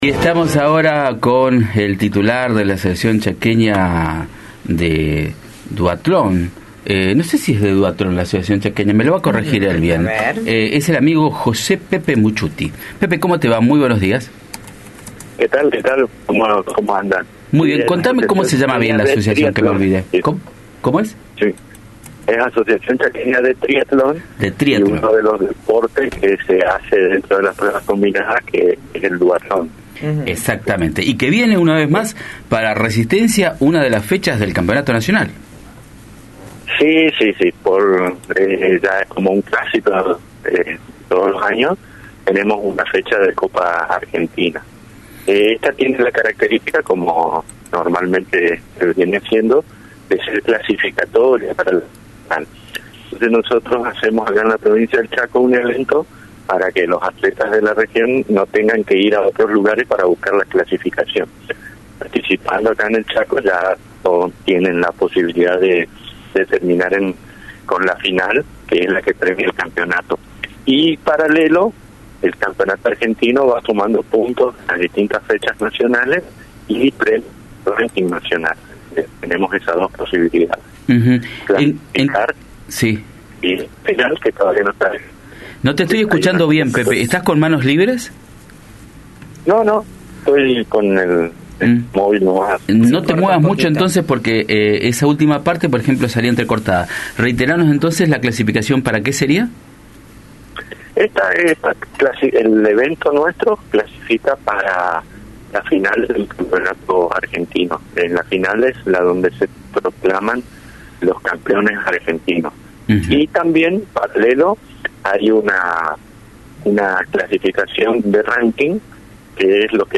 habló al aire de Radio Facundo Quiroga respecto de la Copa Argentina de Duatlón.